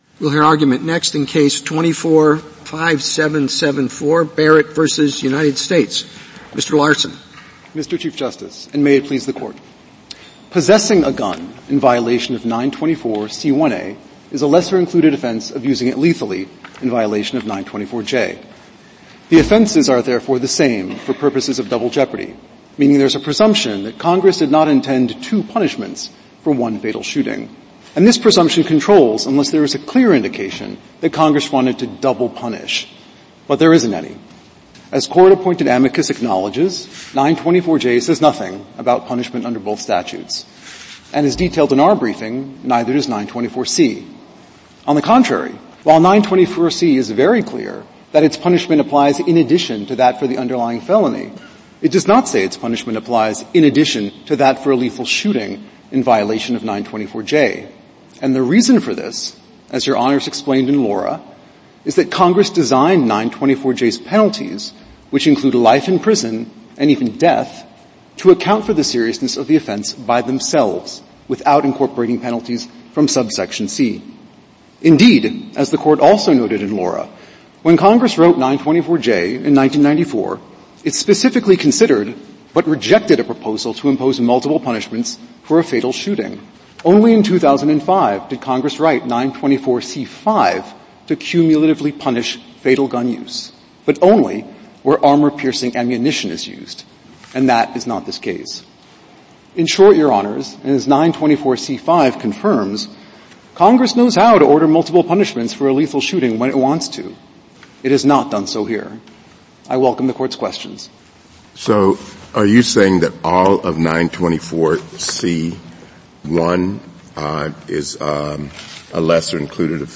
Supreme Court Oral Arguments Podcast